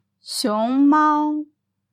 Xióng māo
シィォン マオ